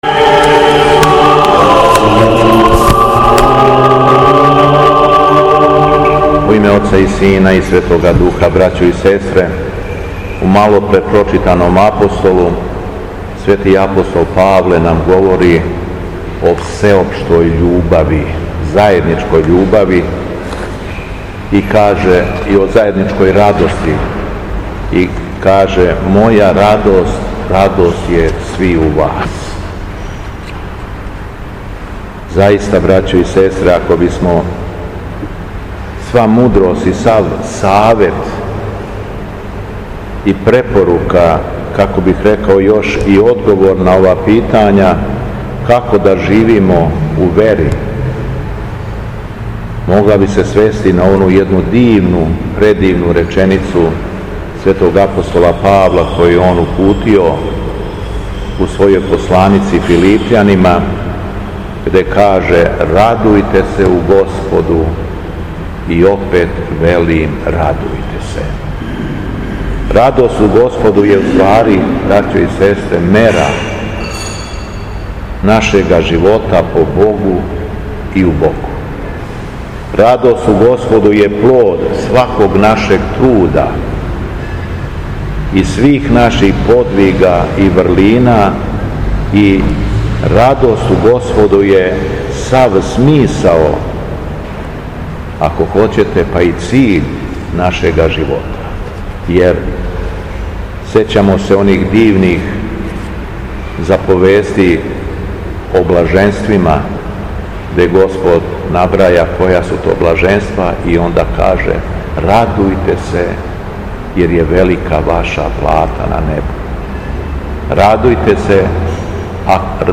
У понедељак једанаести по Духовима, када наша света Црква прославља претпразништво празника Преображења Господа Христа, Његово Високопреосвештенство Митрополит шумадијски Господин Јован служио је свету архијерејску литургију у храму Светога Саве у крагујевачком насељу Аеродром.
Беседа Његовог Високопреосвештенства Митрополита шумадијског г. Јована
По прочитаном Јеванђељу Митрополит се обратио верном народу надахутом беседом, у којој је између осталог рекао следеће: